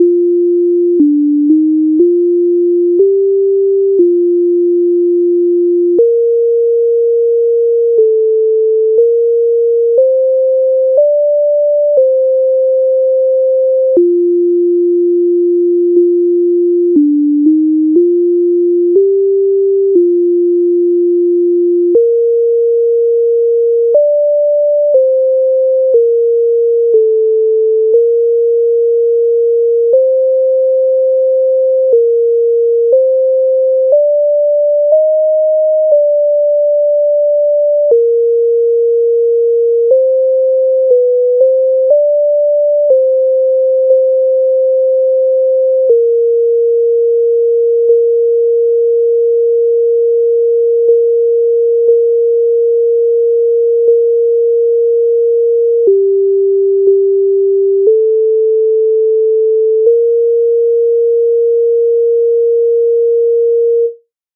MIDI файл завантажено в тональності B-dur